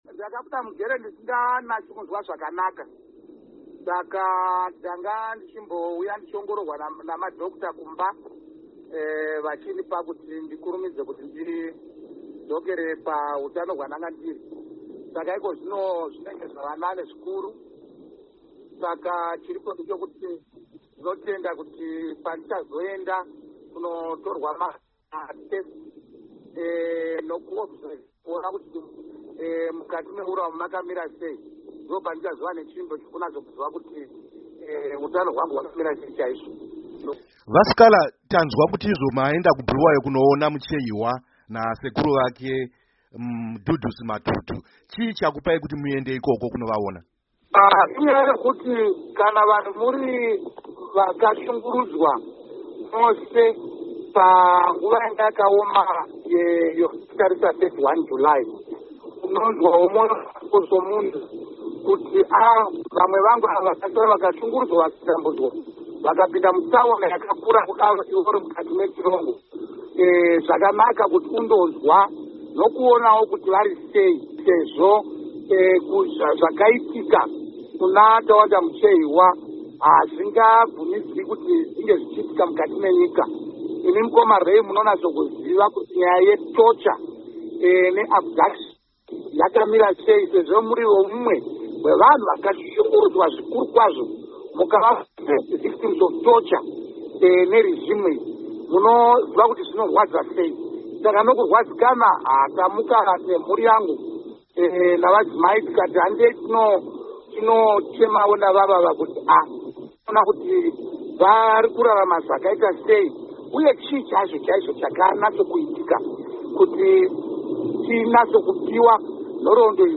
Hurukuro naVaJob Sikhala